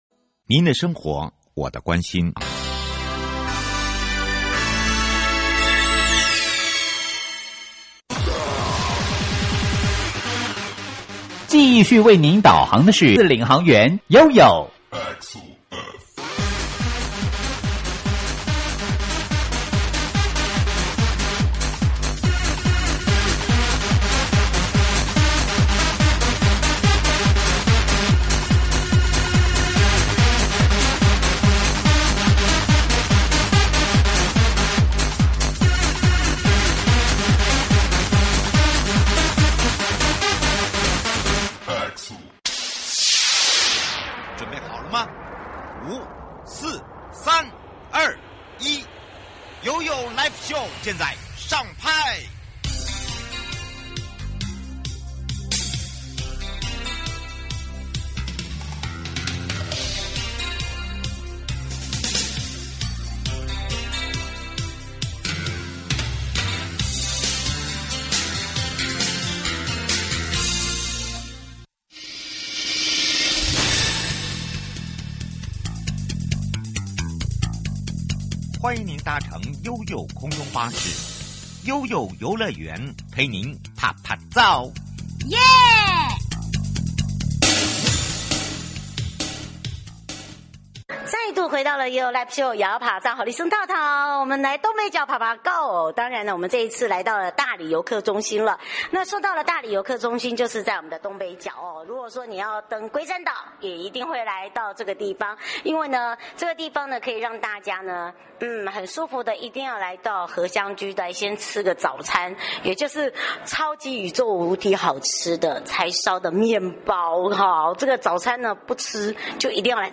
11/2-12/1「芒」著與你一起慢慢走古道「花」時間 受訪者： 1.